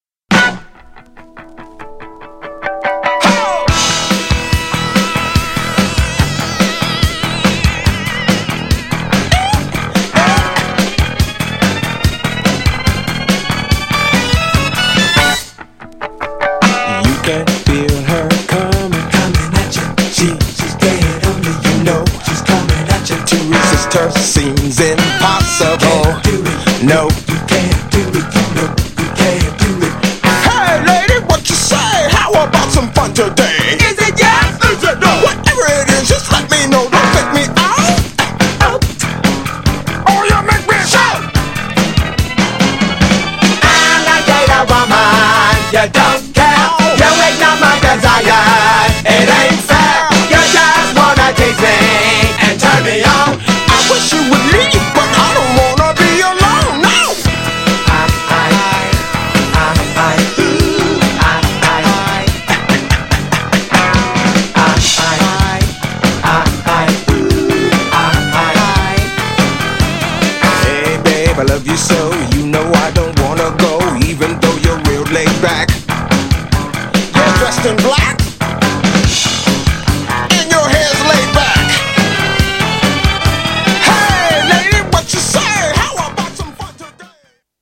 GENRE Dance Classic
BPM 106〜110BPM
FUNKグループ # GROOVY # HIPHOP好きに # P-FUNK # ダンサー向け # ブラック